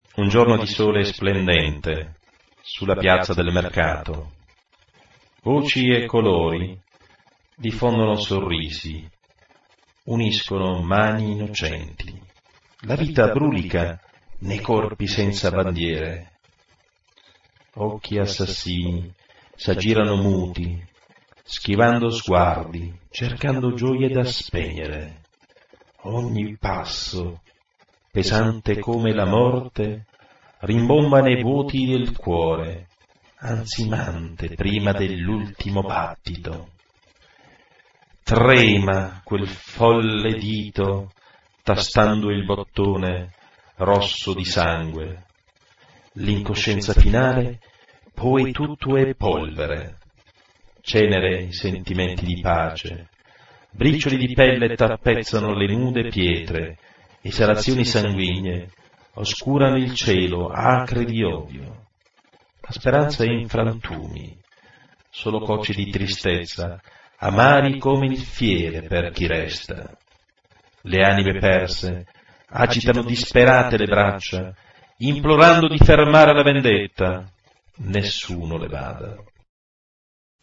Poesia sonora di scottante e drammatica attualità.